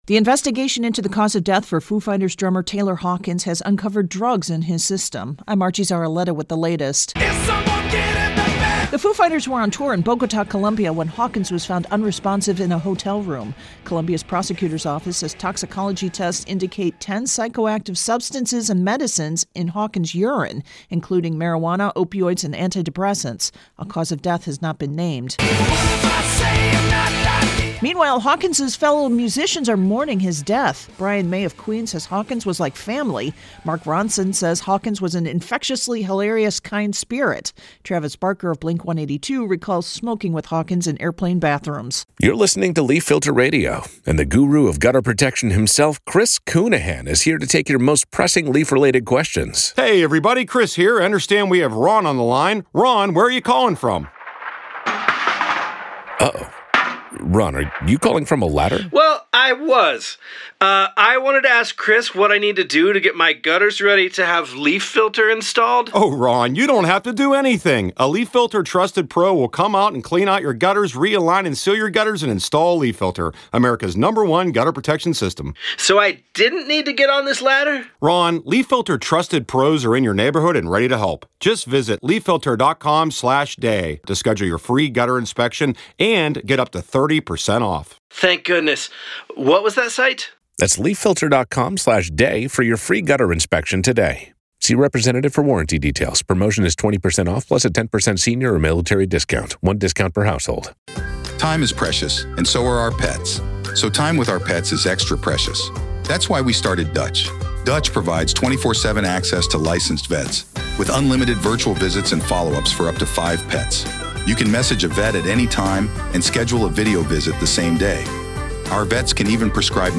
Obit Taylor Hawkins wrap and voicer Sunday